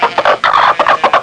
CHICKEN2.mp3